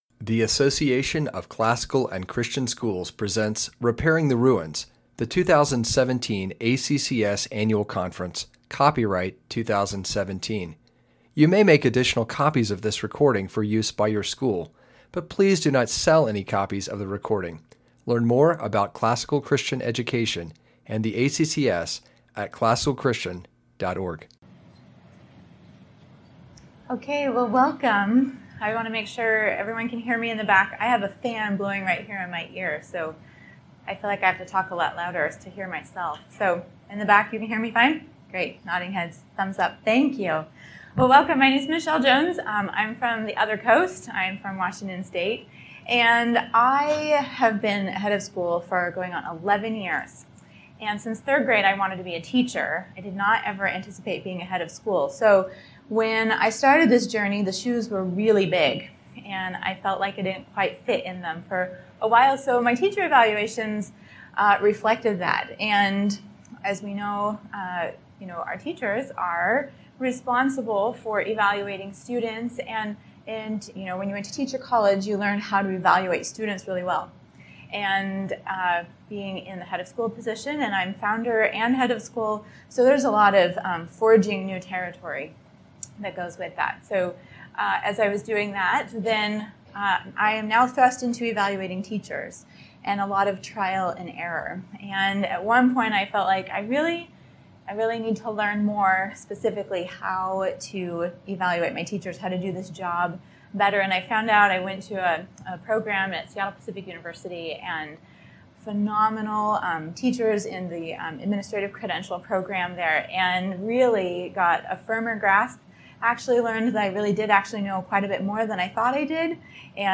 2017 Workshop Talk | 0:53:48 | Training & Certification